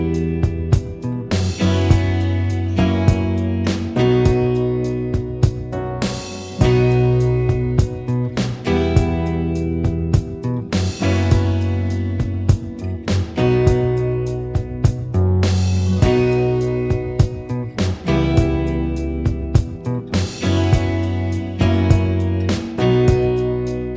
Minus Guitars Soft Rock 5:36 Buy £1.50